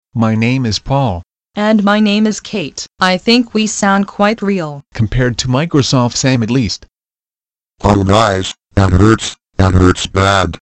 Has a bit of Microsoft Sam in it, awww yeah, gotta get a piece of that action in here.
Computer Talk.mp3